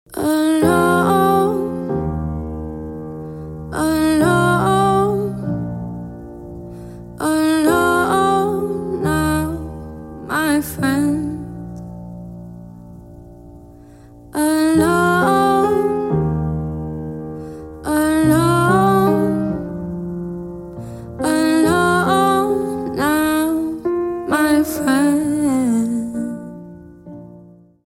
Спокойные И Тихие Рингтоны » # Грустные Рингтоны
Поп Рингтоны